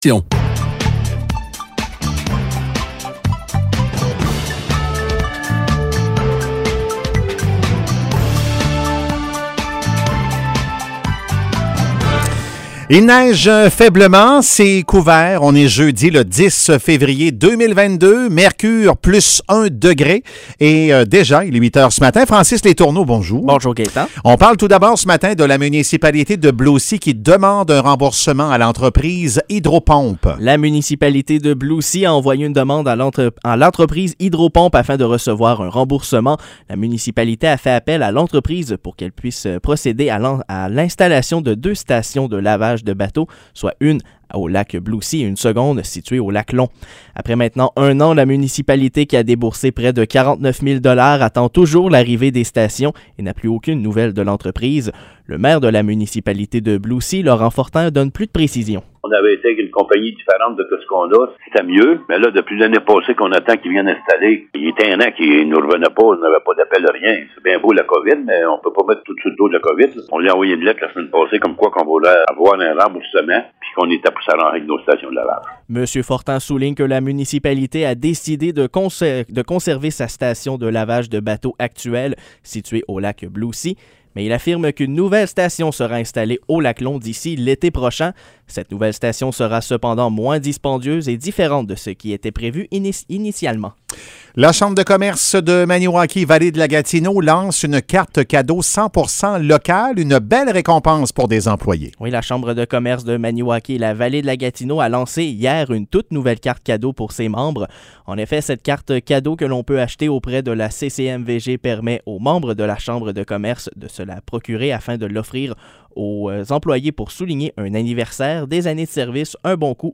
Nouvelles locales - 10 février 2022 - 8 h